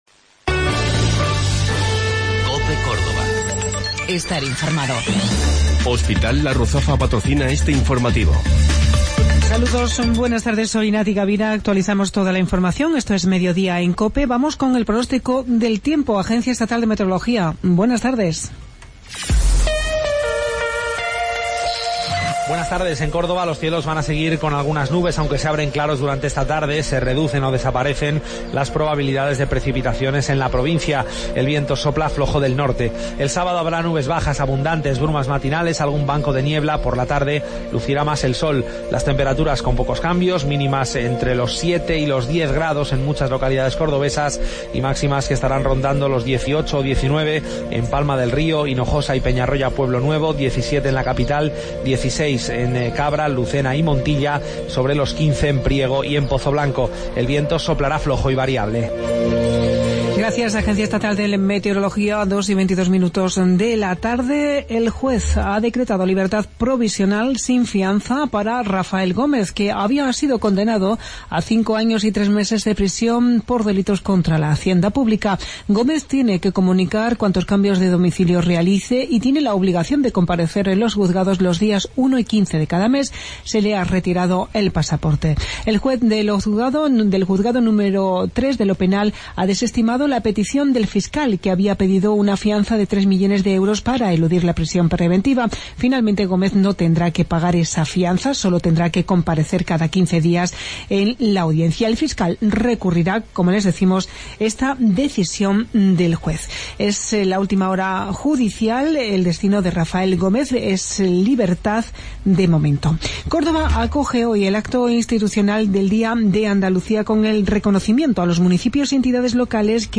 Mediodía en Cope. Informativo local 24 de Febrero 2017